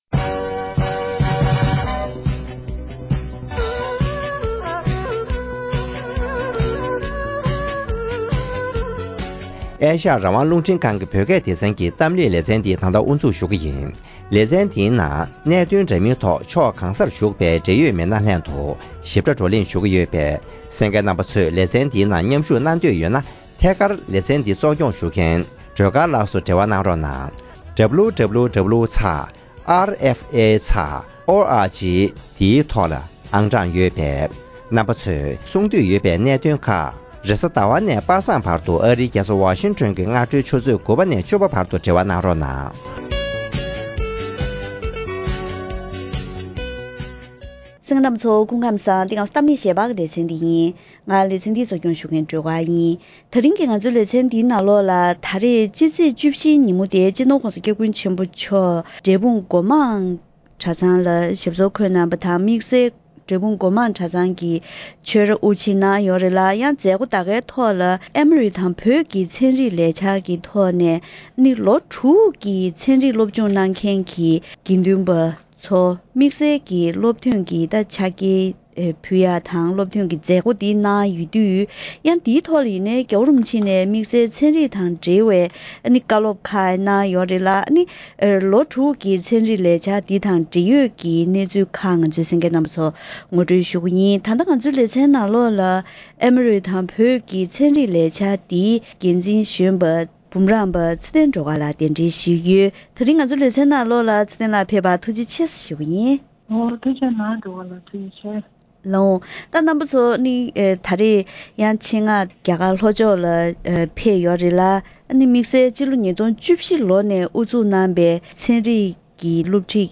༧གོང་ས་མཆོག་ནས་ནང་ཆོས་དང་ཚན་རིག་ཟུང་འབྲེལ་ཐོག་ནས་སྐྱོད་ཐུབ་ན་འཛམ་གླིང་འདིར་ནང་པའི་ངོས་ནས་ཞབས་འདེགས་ཤིག་བསྒྲུབ་ཐུབ་རྒྱུ་ཡིན་པ་བཀའ་སློབ་བསྩལ་བ།